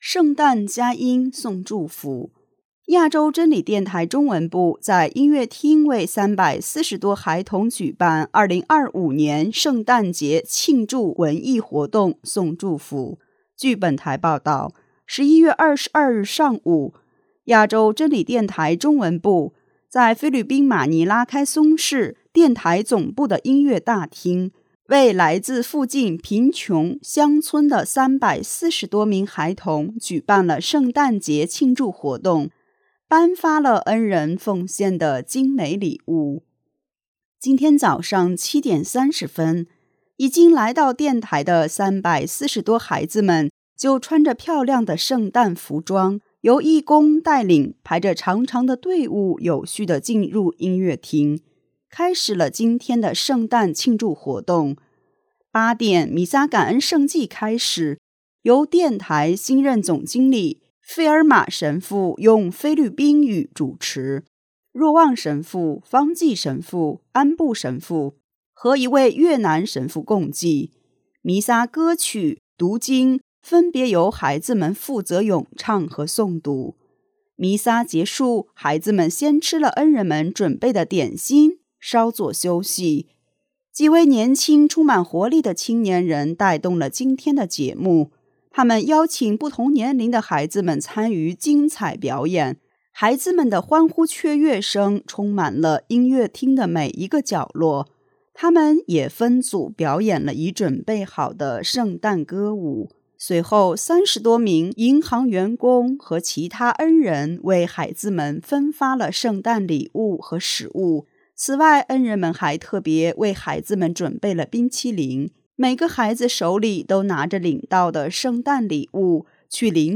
据本台报道，1122日上午，亚洲真理电台中文部在菲律宾马尼拉奎松市电台总部的音乐大厅，为来自附近贫穷乡村的340多名孩童举办了圣诞节庆祝活动，颁发了恩人奉献的精美礼物。